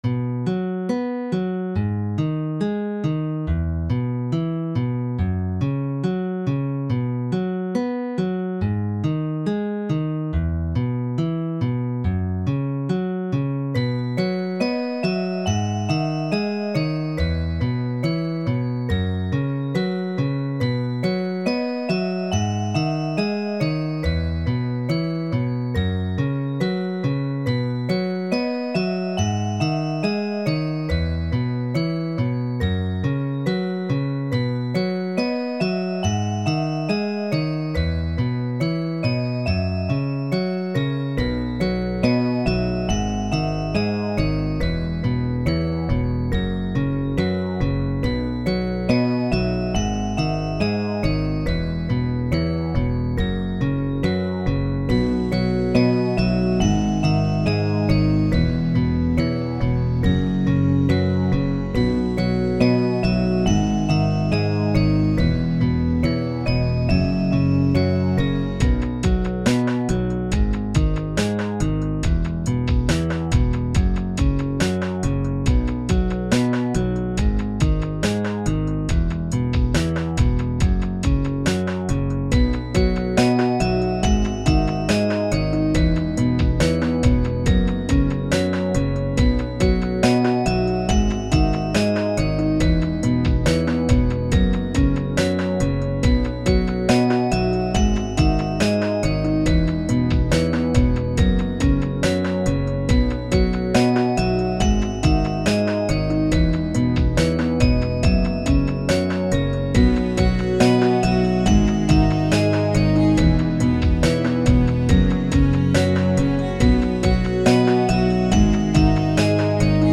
A song made with different soundfonts and vsts
lofi